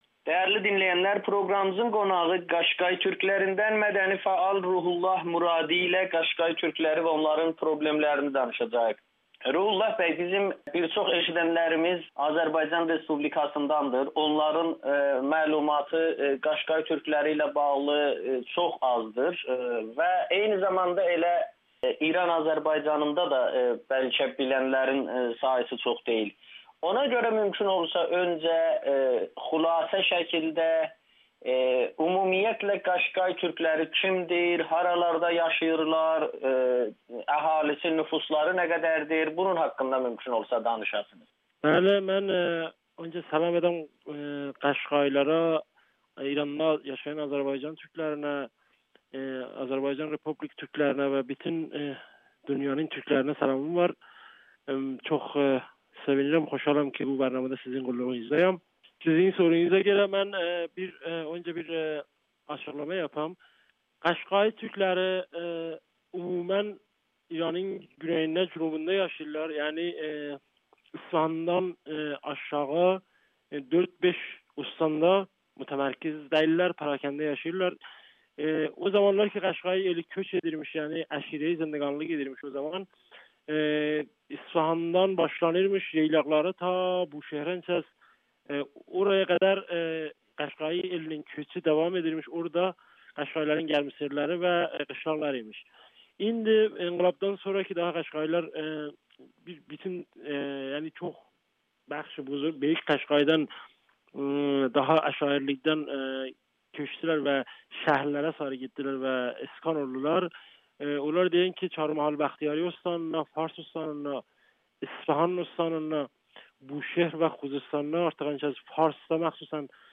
Qaşqay türklərinin mədəni fəalı Amerikanın Səsinə müsahibə mənsub oduğu cəmiyyətin müasir və kemiş tarixi, mədəniyyəti və həyat tərzini anlatmaqla yanaşı onların üzləşdiyi iqtisadi çətinliklər habelə ictimai və mədəni problemlərini şərh edib.